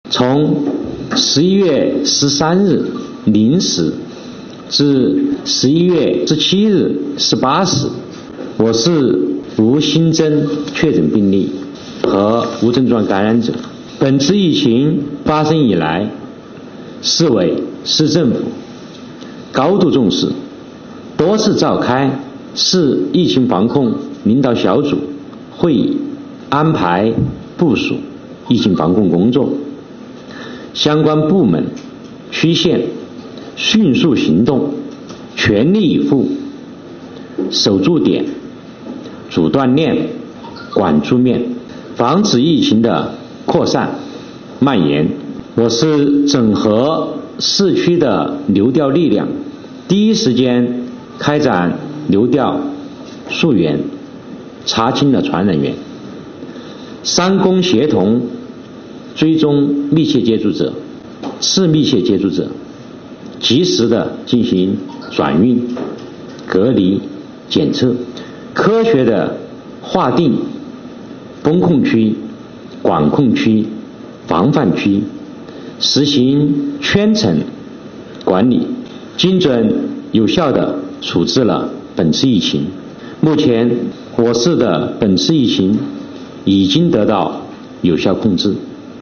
11月17日，重庆市政府新闻办举行第96场重庆市新冠肺炎疫情防控工作新闻发布会，介绍重庆疫情防控相关情况。
李 畔 市卫生健康委副主任